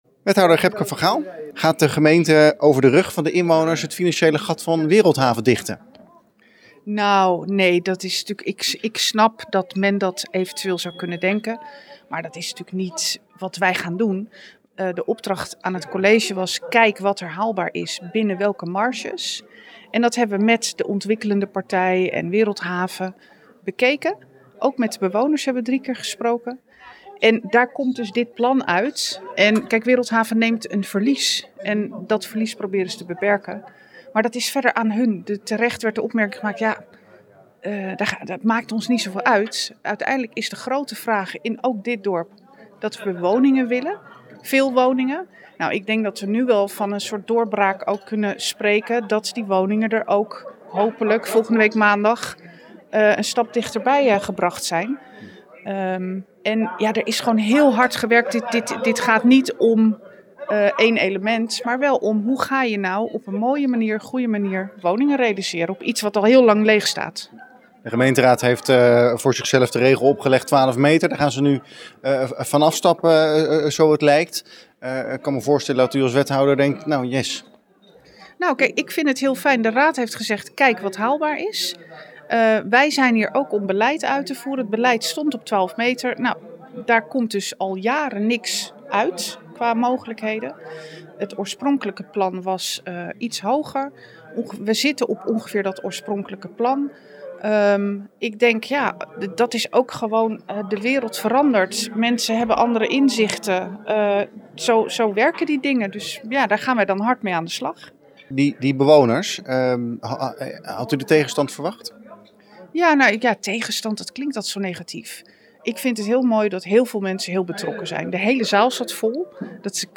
Wethouder Gebke van Gaal over de bouwplannen.